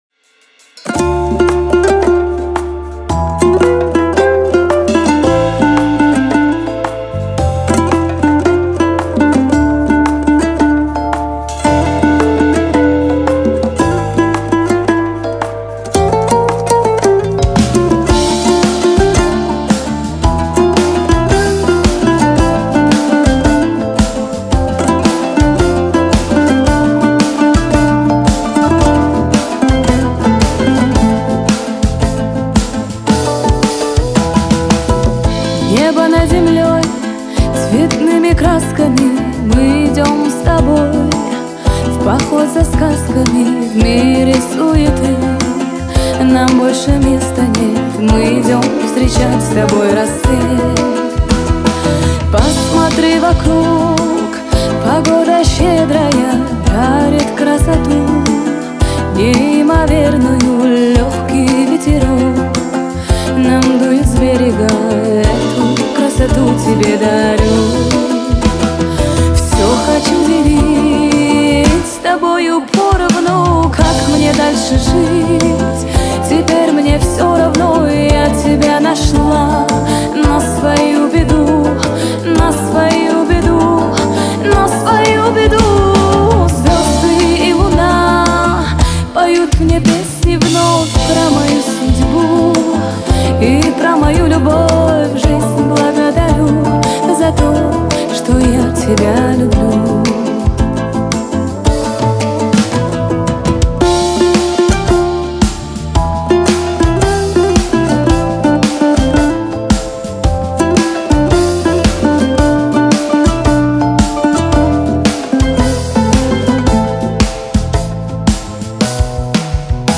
это яркий пример казахского поп-фолка